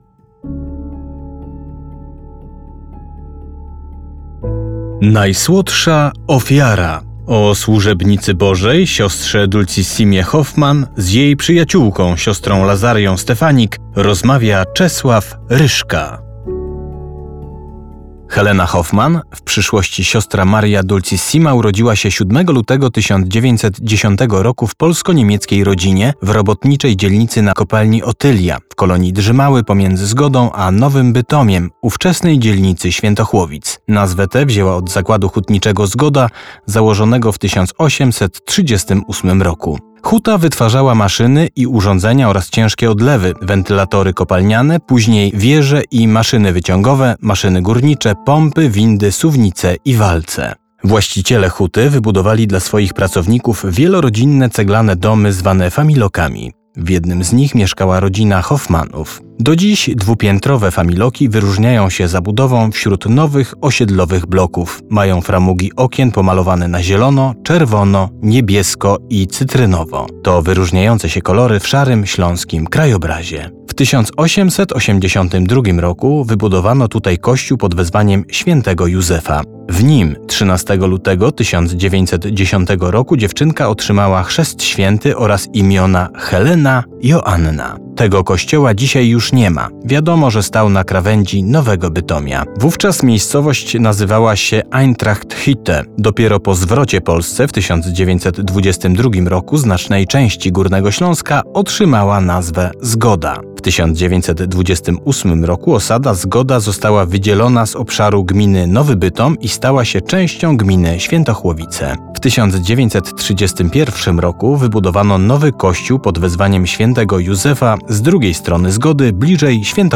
Propozycją duszpasterską Radia Rodzina na Wielki Post jest specjalny audiobook pt. „Dulcissima -Najsłodsza Ofiara”.